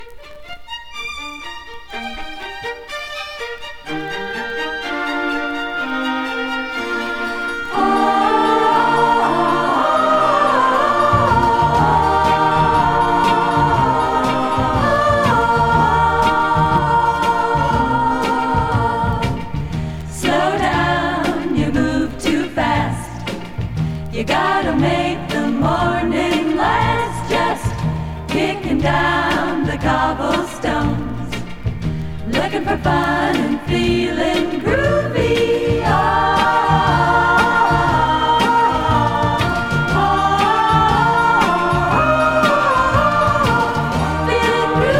12人の女性コーラスを配し各面のラスト以外はボーカルを前面に。
洒落た雰囲気に嬉しくなる素敵なソフト・ポップス集。"
Jazz, Pop, Vocal, Easy Listening　USA　12inchレコード　33rpm　Stereo